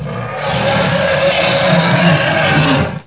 roar3.wav